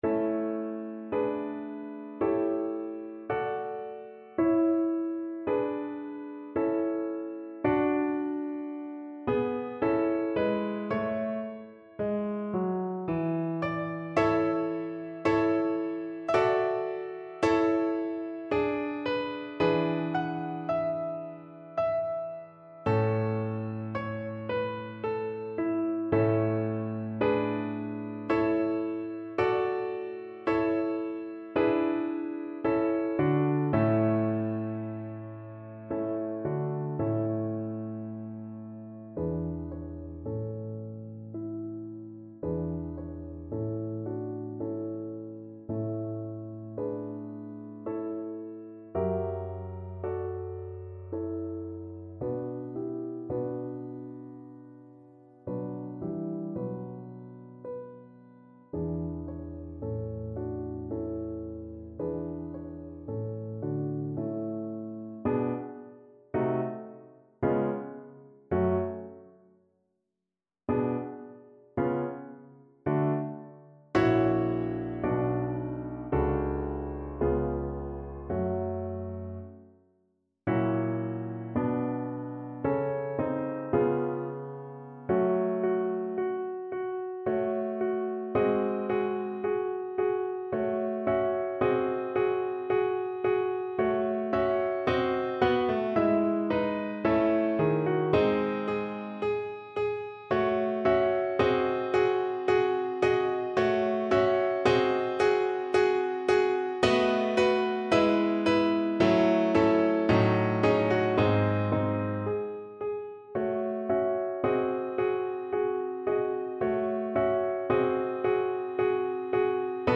Play (or use space bar on your keyboard) Pause Music Playalong - Piano Accompaniment Playalong Band Accompaniment not yet available transpose reset tempo print settings full screen
Violin
A major (Sounding Pitch) (View more A major Music for Violin )
Moderato =80
3/4 (View more 3/4 Music)
Classical (View more Classical Violin Music)